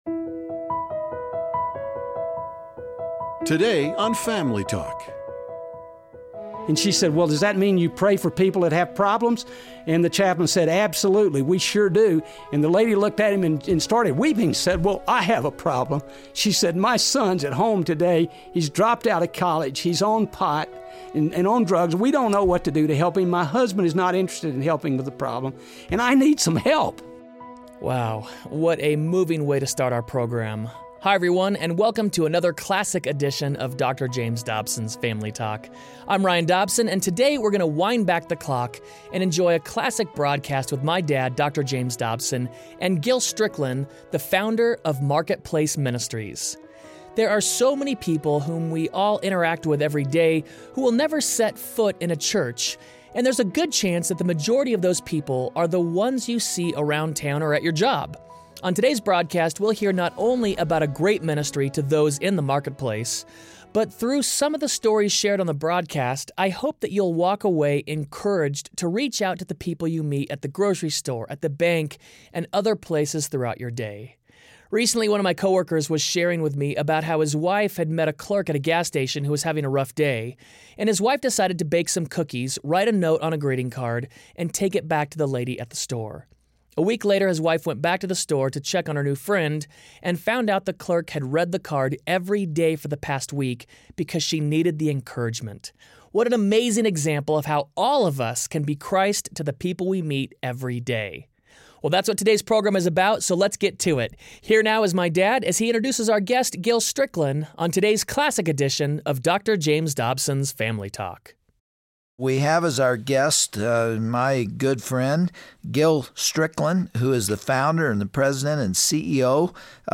Every day we have the opportunity to reach out to people who may never step foot in a church. On today’s edition of Family Talk, Dr. James Dobson interviews